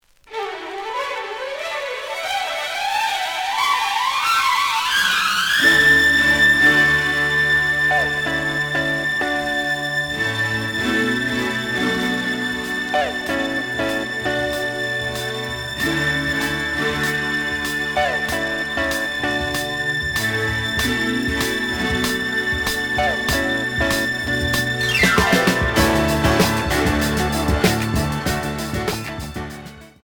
試聴は実際のレコードから録音しています。
●Format: 7 inch
●Genre: Disco